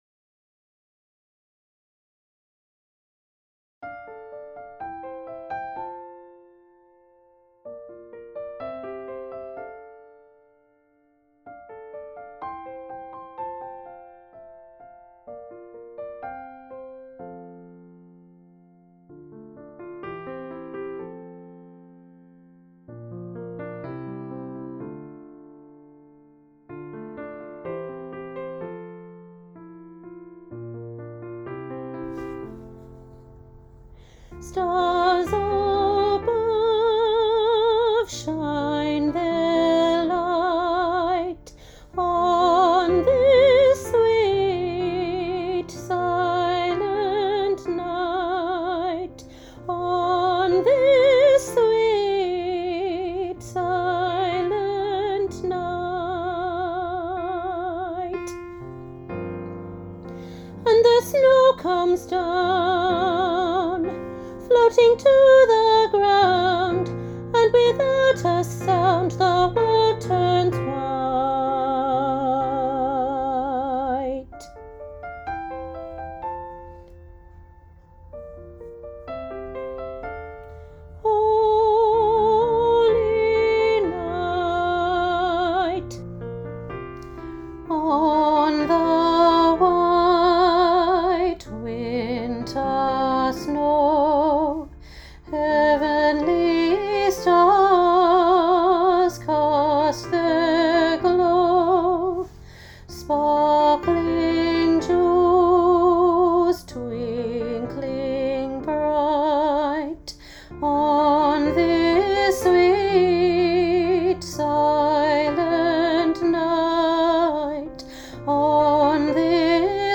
Elementary Choir – Sweet Silent Night, Part 2
Elementary-Choir-Sweet-Silent-Night-Part-2.mp3